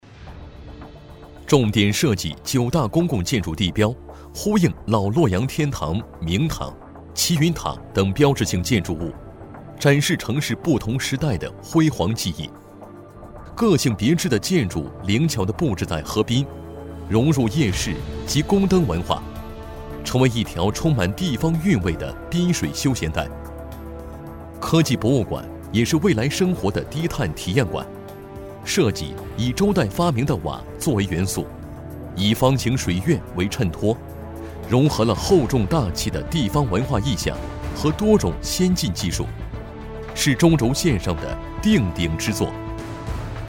男国291_多媒体_项目规划_城市规划.mp3